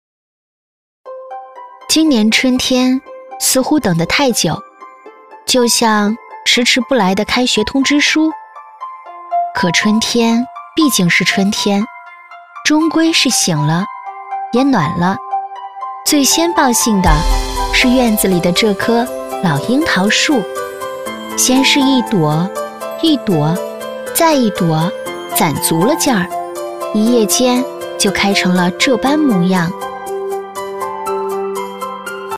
外籍美式英语配音
配音风格： 自然